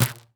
kicker_a.mp3